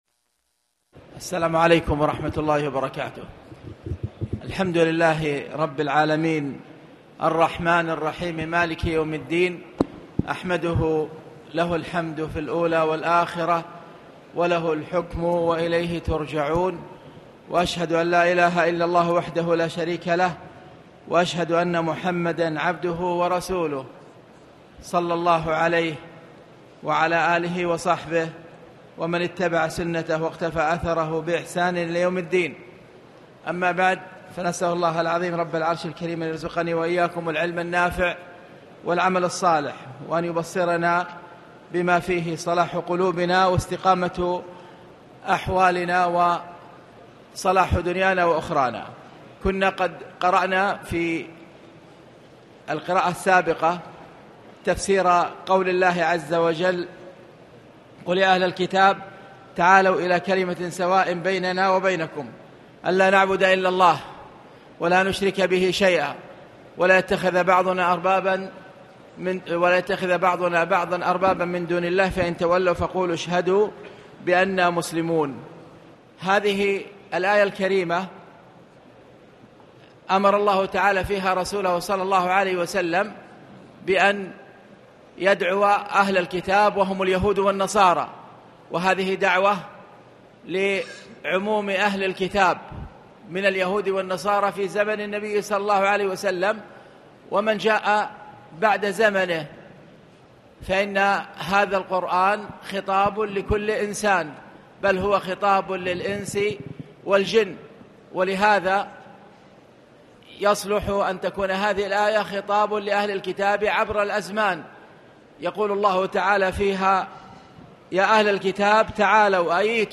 تاريخ النشر ١٥ رمضان ١٤٣٨ هـ المكان: المسجد الحرام الشيخ